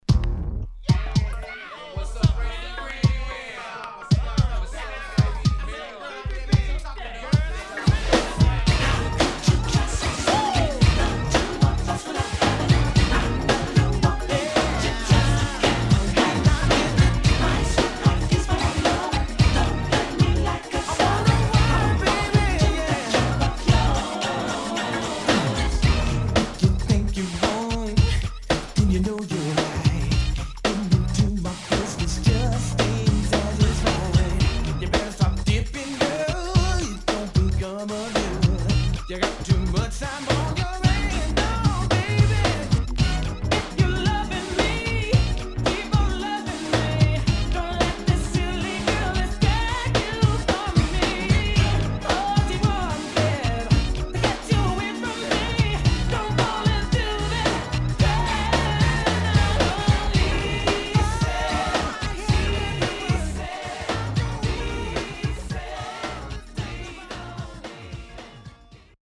92年R&B.12623
SIDE A:所々チリノイズ、プチノイズ入ります。